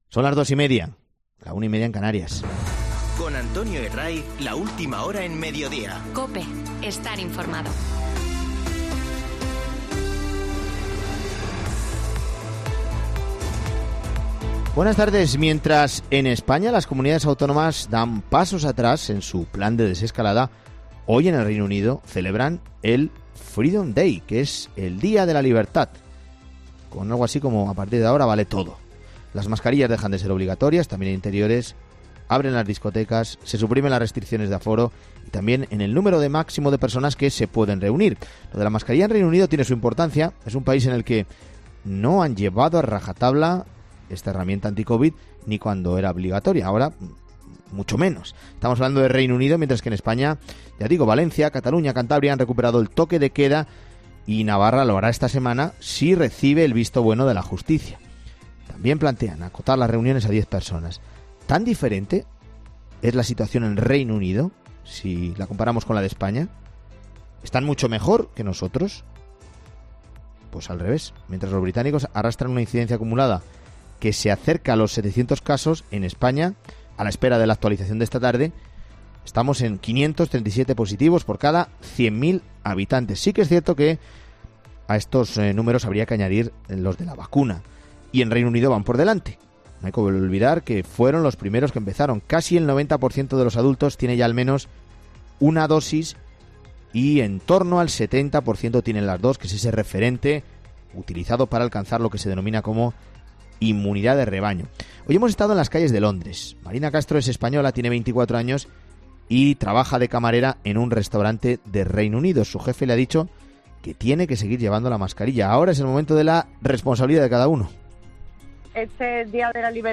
Hoy hemos estado en las calles de Londres.